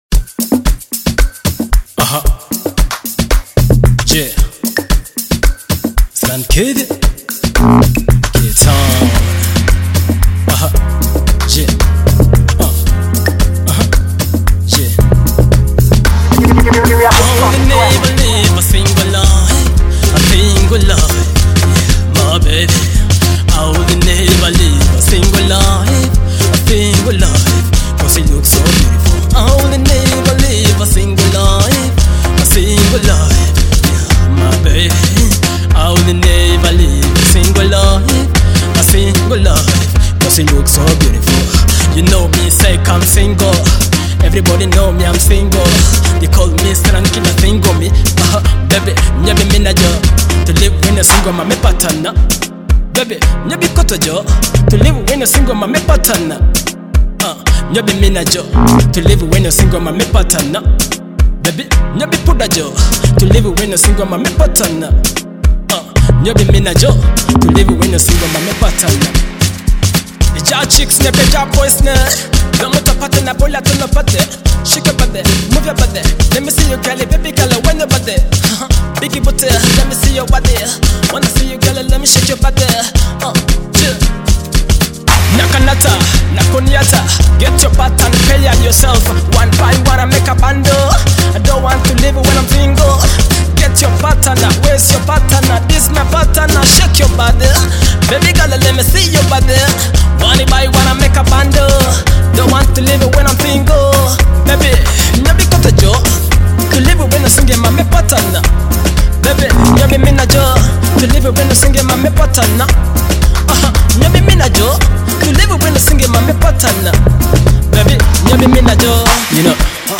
Afrobeat and Amapiano-inspired anthem